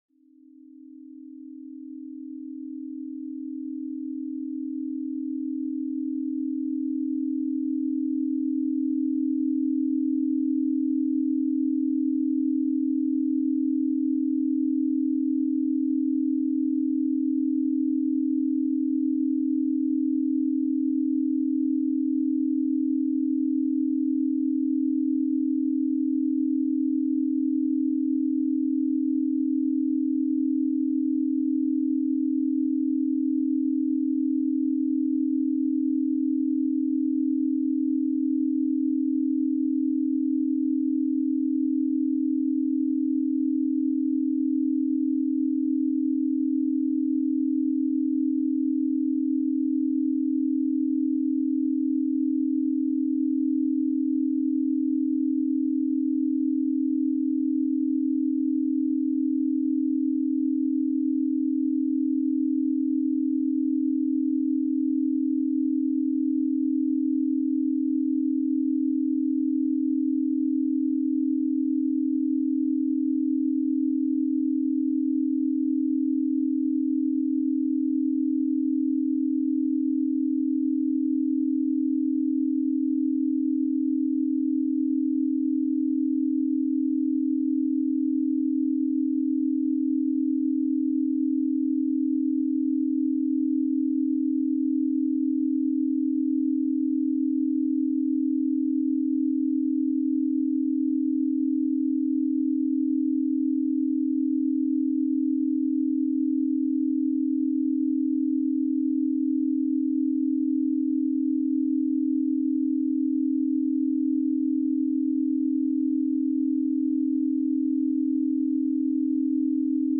Pluie urbaine apaisante · méthode éprouvée pour focus profond en ville